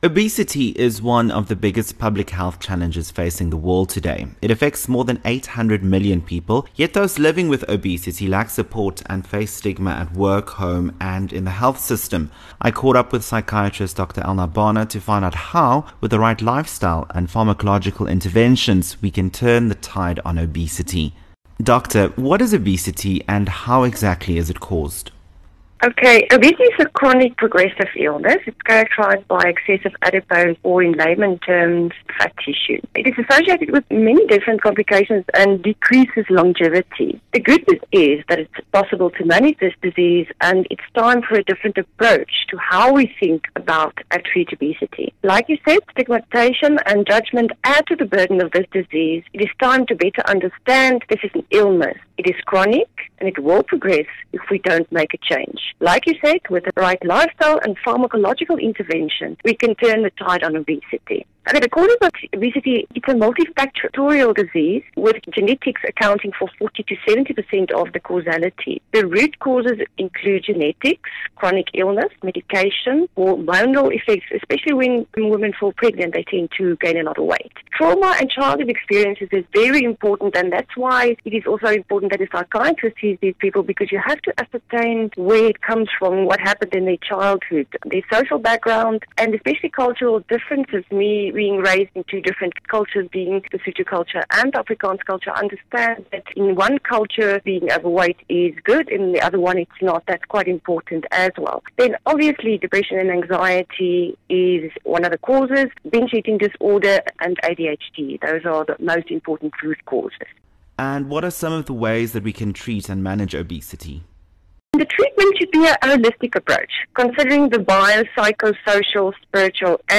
psychiatrist